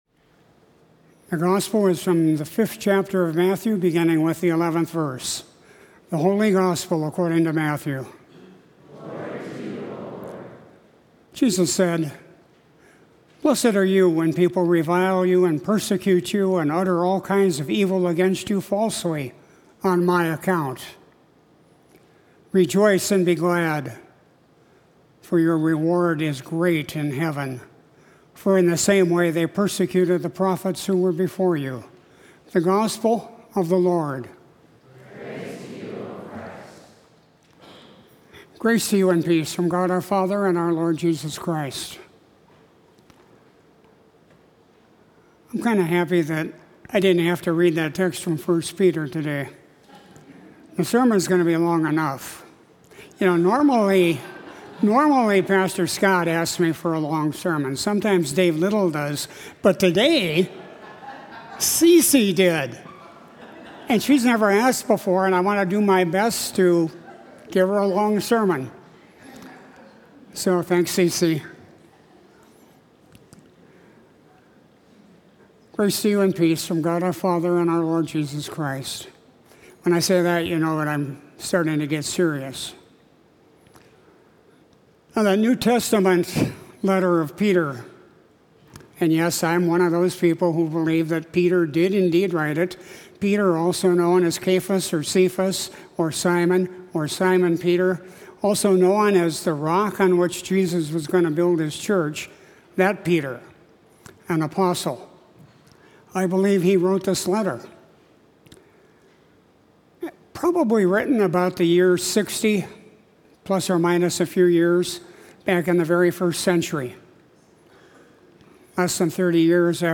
Sermon for Sunday, July 10, 2022